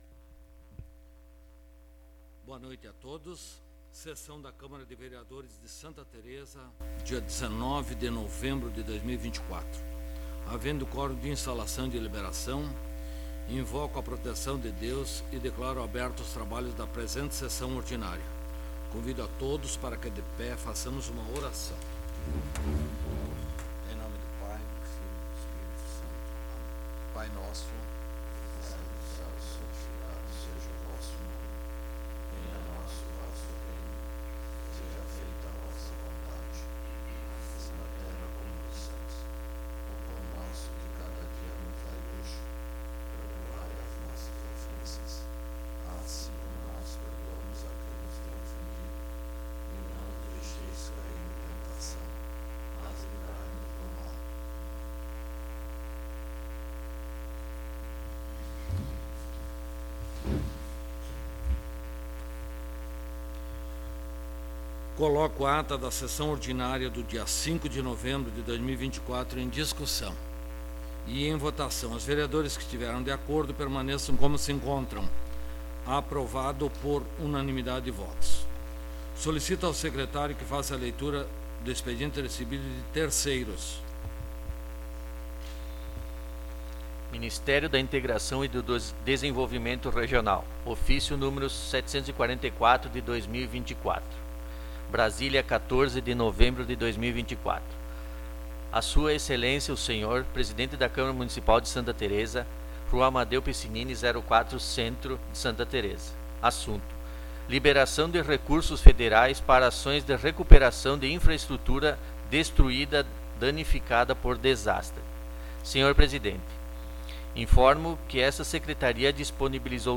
20° Sessão Ordinária de 2024